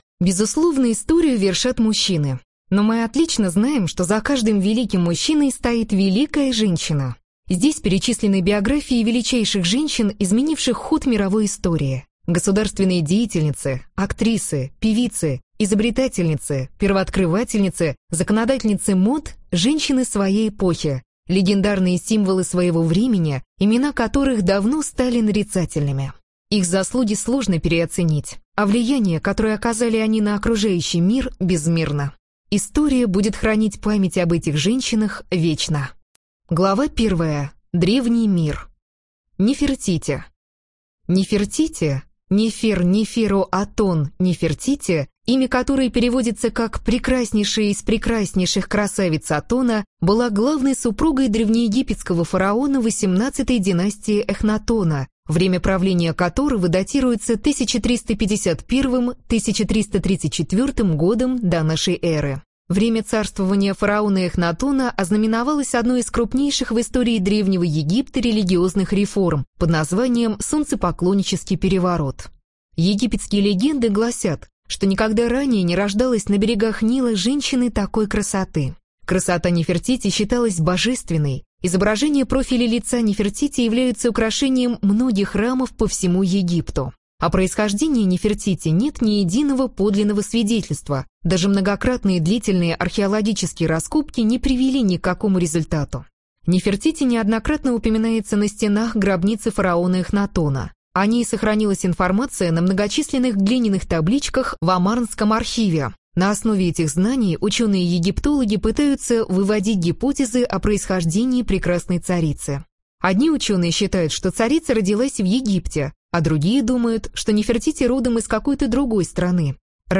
Аудиокнига Женщины, изменившие мир | Библиотека аудиокниг
Прослушать и бесплатно скачать фрагмент аудиокниги